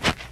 alien-biomes / sound / walking / snow-10.ogg
snow-10.ogg